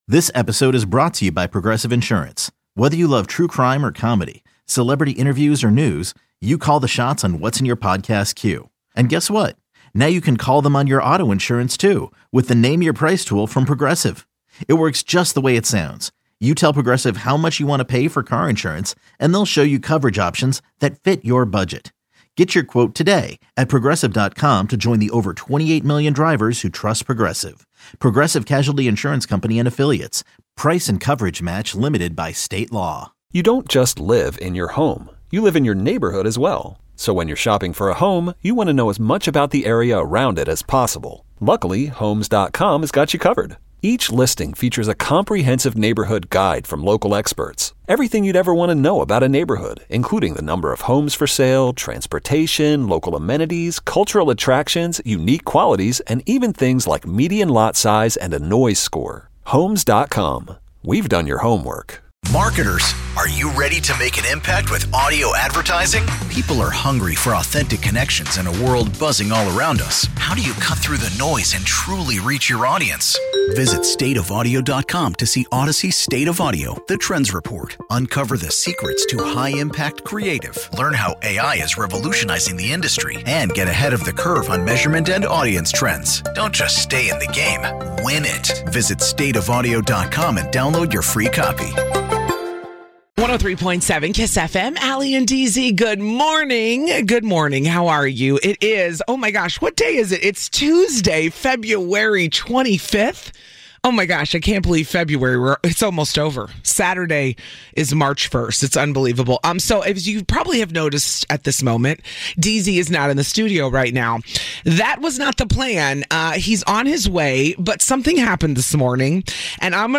No commercials, no music.